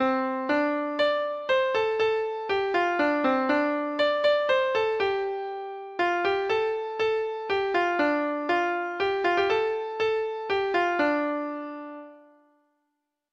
Traditional Trad. Young Benjie Treble Clef Instrument version
Folk Songs from 'Digital Tradition' Letter Y Young Benjie